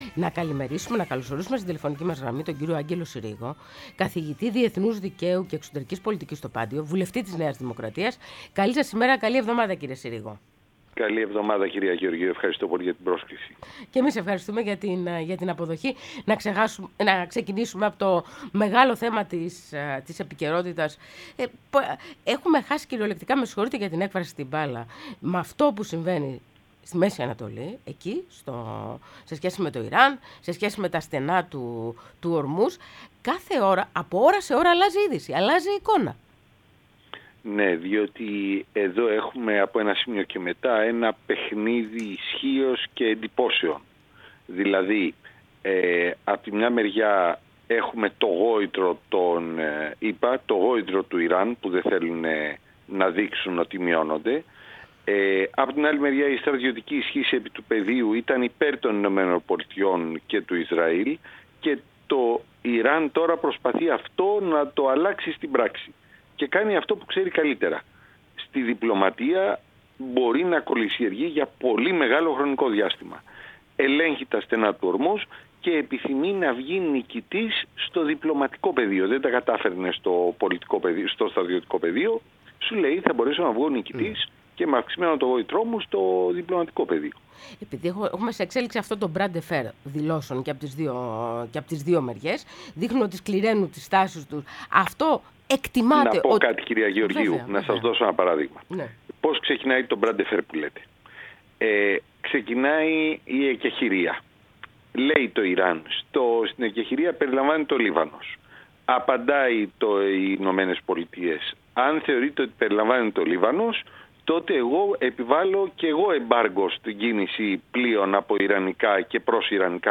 Ο Άγγελος Συρίγος, Καθηγητής Διεθνούς Δικαίου και Εξωτερικής Πολιτικής στο Πάντειο και βουλεύτης της ΝΔ, μίλησε στην εκπομπή «Πρωινές Διαδρομές»